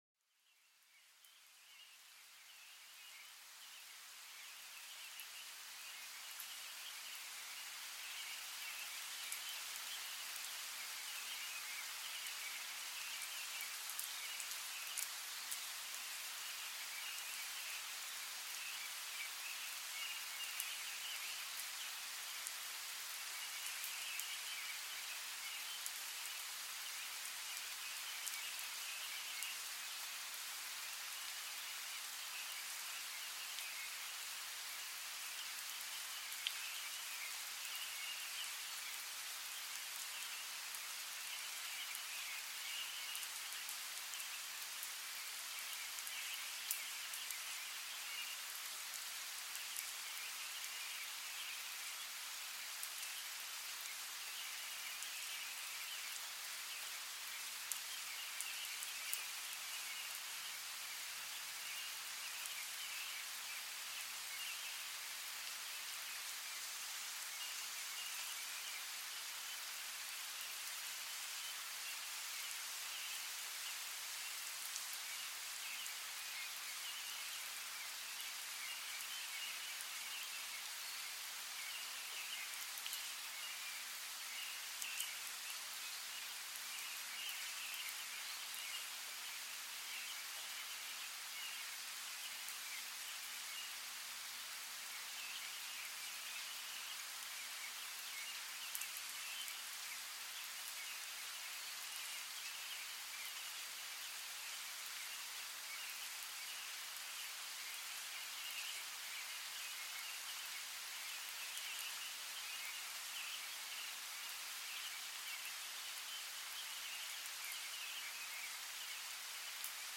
Sumérgete en el corazón de un bosque frondoso, donde el suave sonido de la lluvia teje una melodía calmante, invitando a la calma y la reflexión. Cada gota cuenta una historia, abriéndose camino a través del follaje para unirse a la tierra nutritiva, despertando los sentidos a la belleza oculta de la naturaleza.